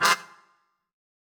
GS_MuteHorn-Fmin9.wav